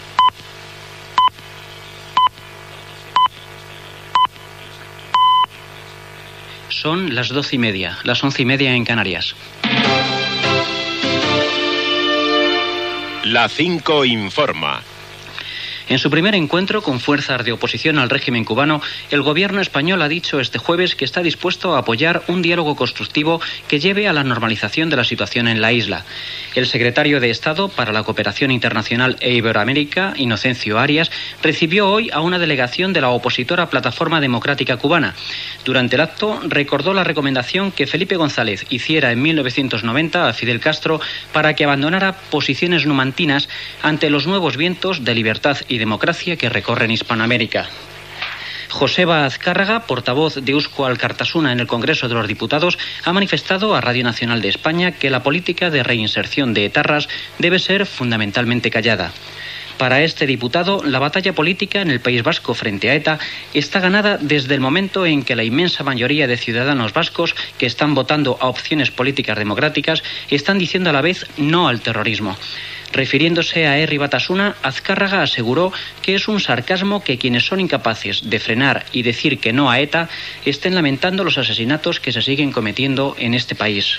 Hora, careta del programa, membres de la Plataforma Democrática Cubana rebuts pel secretari d'Estat de Cooperació Internacional, Inocencio Arias; País Basc
Informatiu